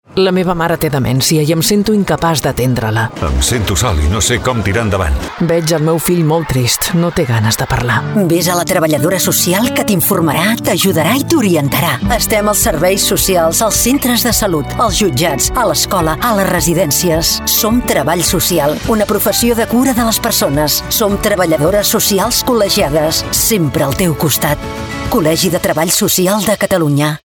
COL·LEGI TREBALL SOCIAL CATALUNYA - anunciràdio.mp3